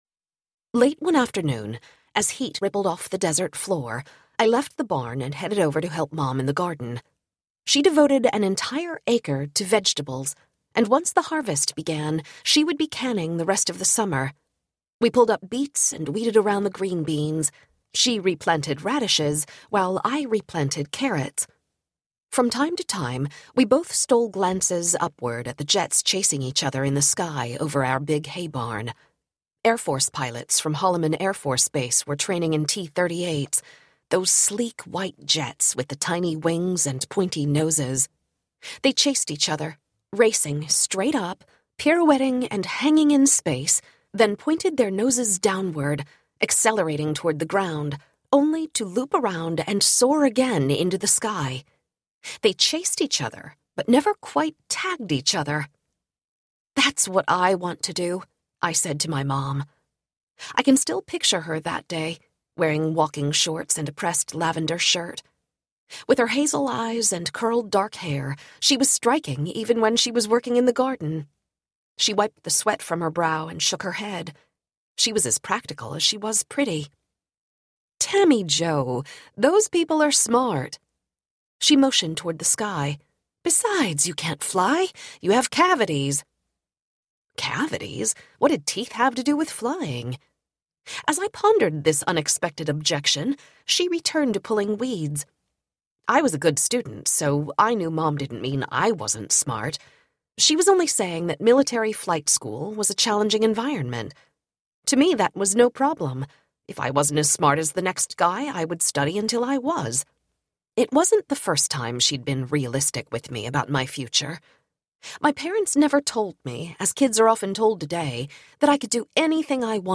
Nerves of Steel Audiobook
Narrator
7.5 Hrs. – Unabridged